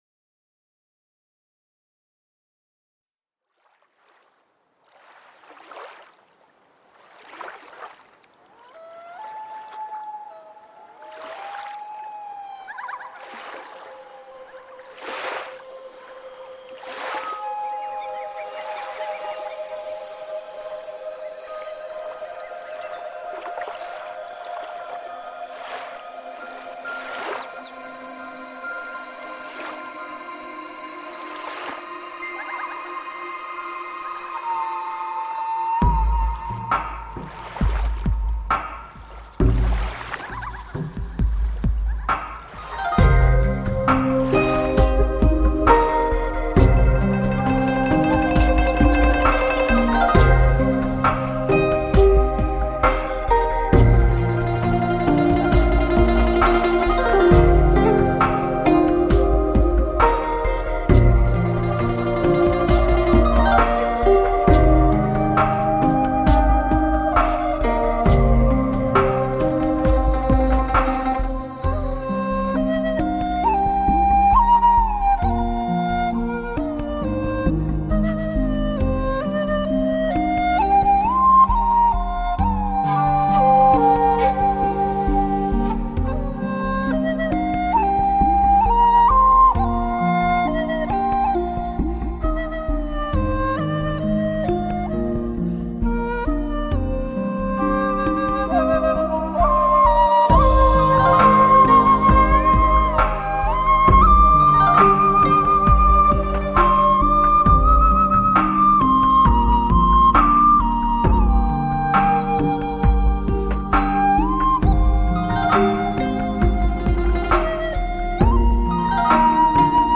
竹笛版
还有个竹笛版，点击可以试听~~~
飞出的乐符缓慢恬静，夹带着丝丝淡淡的忧伤，眼前的一汪清水里。。。。感谢分享